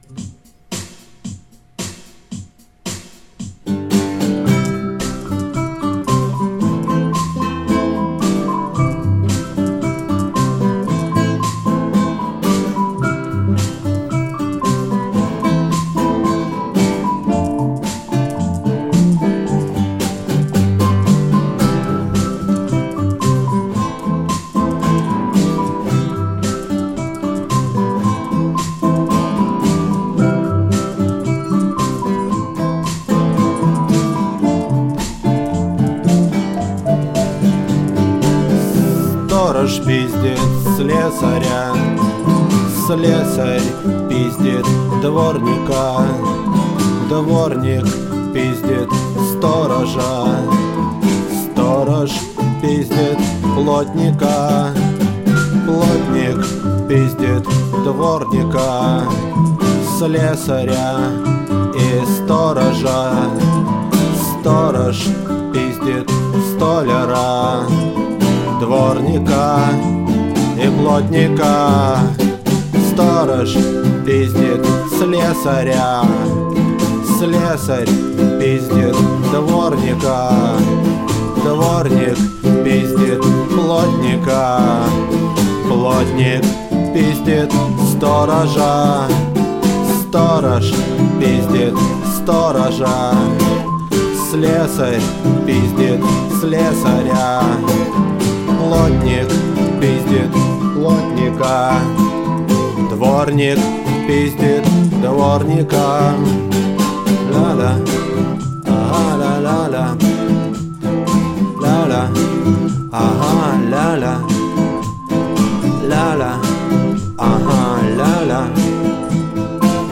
вокал.
клавиши, гитара.
перкуссия, ударные, бас, программирование драм-машины.